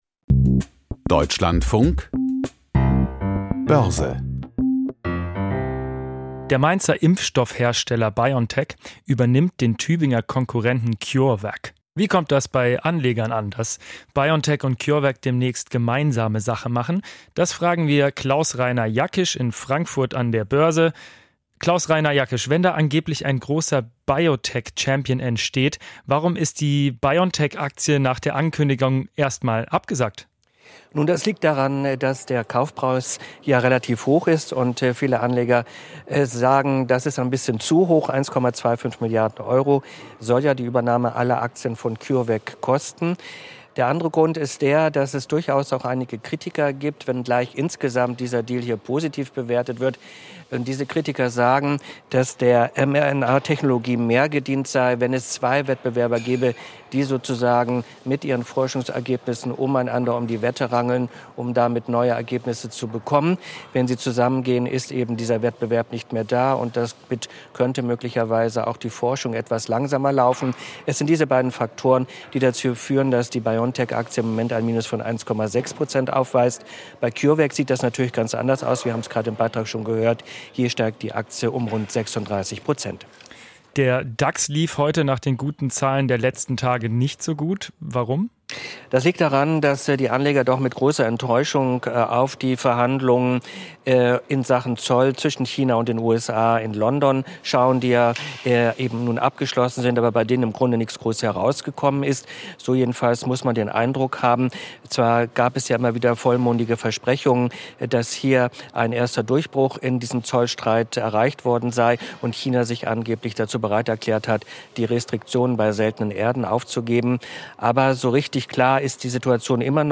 Börsenbericht aus Frankfurt a.M.